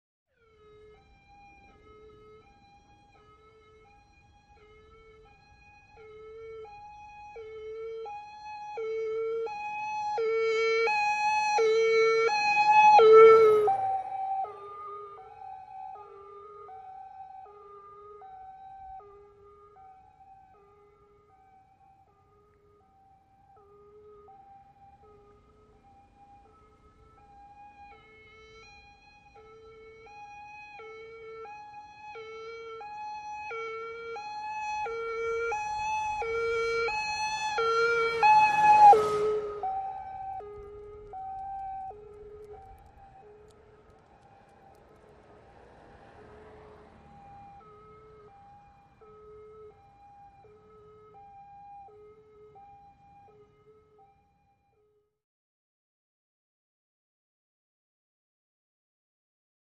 High-low Siren Long In From Distant, Close Doppler By Left To Right Fast. Away To Distant Then Return And By Left To Right Again And Away.